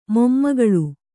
♪ mommagaḷu